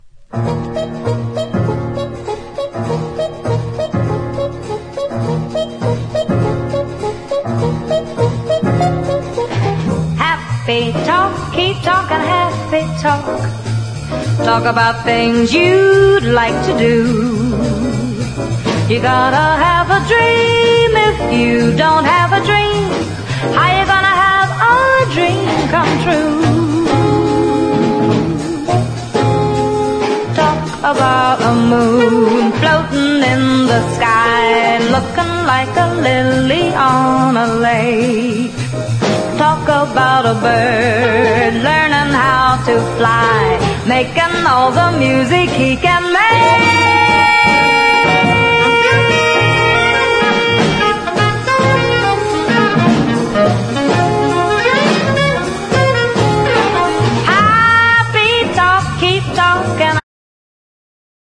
JAZZ / JAZZ VOCAL / SWING / DISCO
ジャイヴィンな軽快80'Sフェイク・スウィング！
ノスタルジックで華麗、でも何処か80'Sなきらめき感とフェイク感が◎なグルーヴィ・チューン揃い。